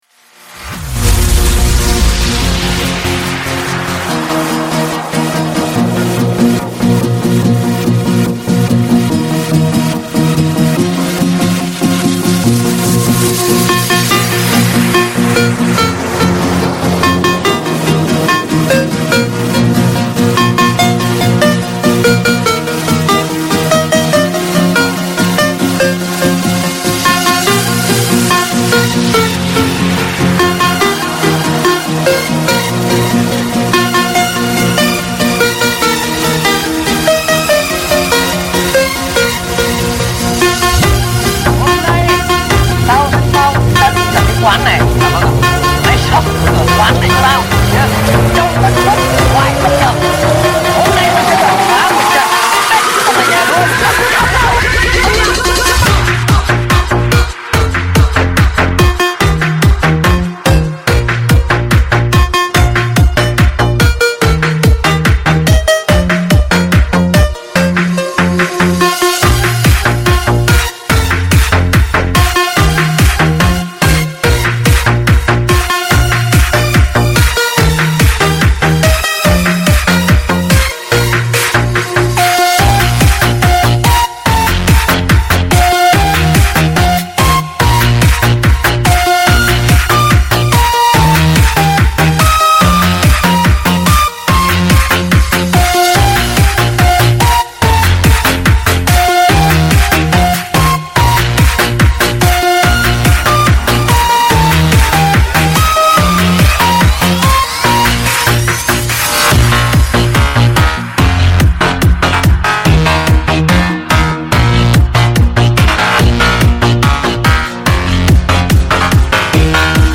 Ремикс праздничной музыки